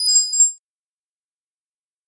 Звуки ввода пароля